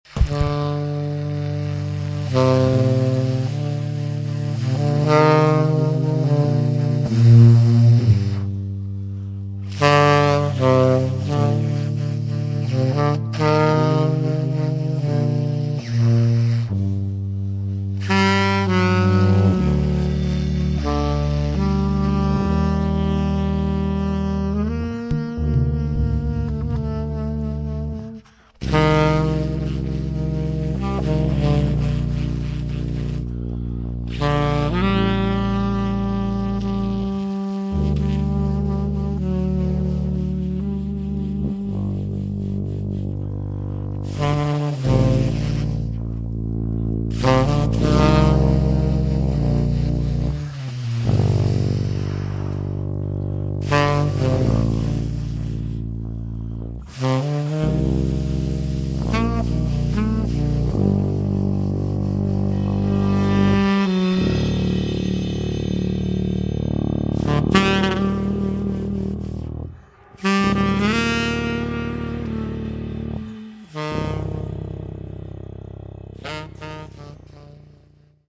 Recorded live in Vancouver, Canada, on february 25 2007
sax tenore
tuba
batteria